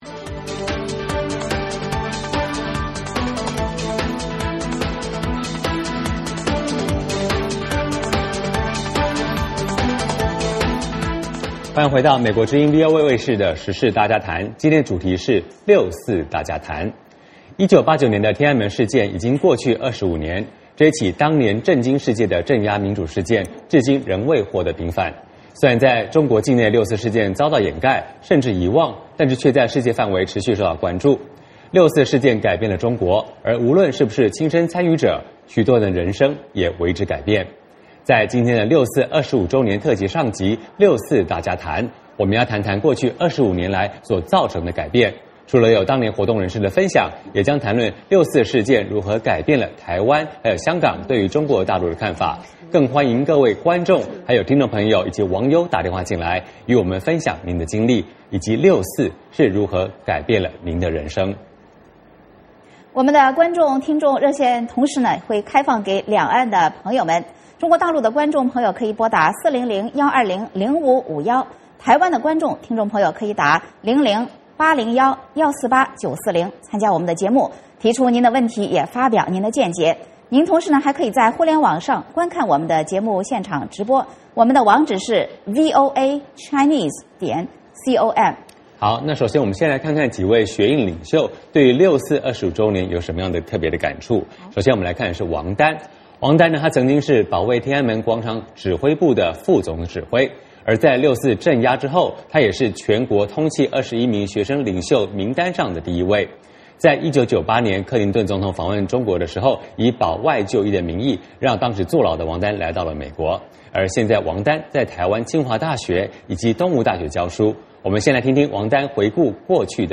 在今天的六四25周年特辑上集：六四大家谈，我们要谈谈当年这起事件25年来造成的改变，除了邀请当年活动人士参与，也将谈论六四事件如何改变了港台和国际社会对于中国大陆的看法，更有各位观众和听众朋友现场来电与美国之音分享个人经历和心路历程。